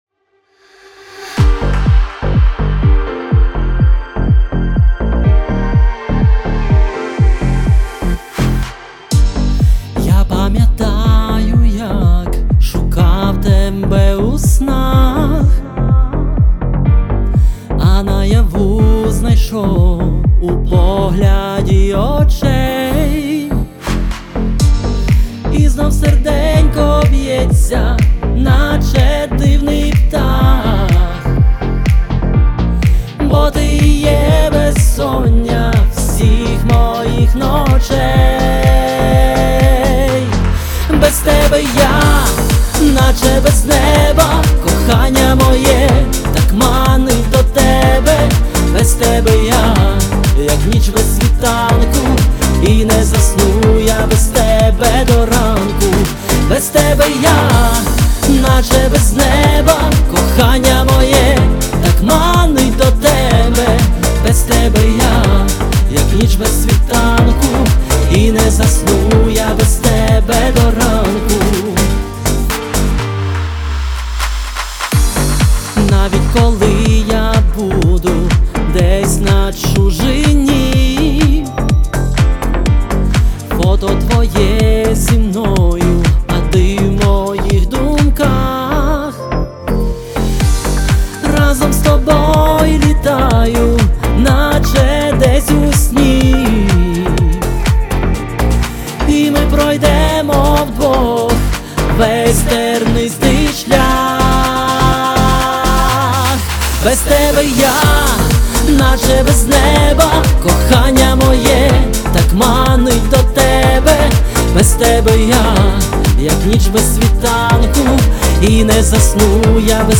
Стиль: Поп